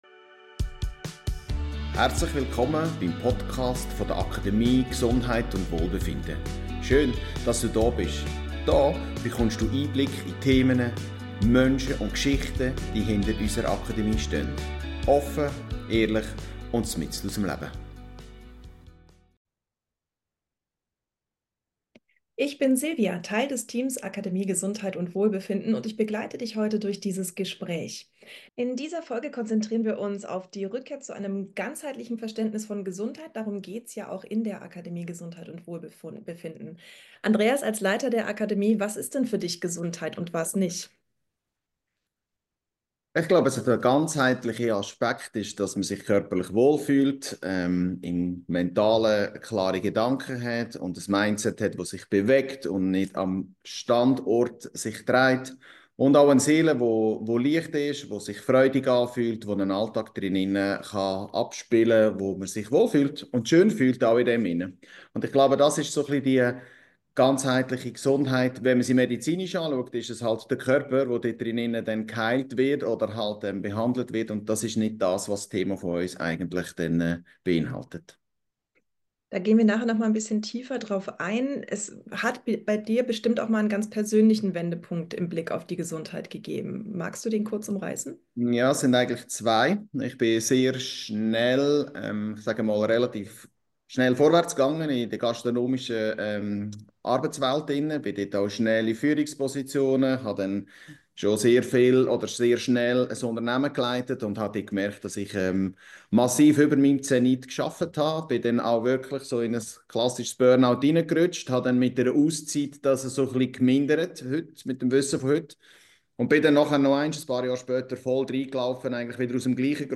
Folge 1: Heilung beginnt innen - Ein Gespräch über Werte, Wandel und Verantwortung ~ Akademie Gesundheit & Wohlbefinden Podcast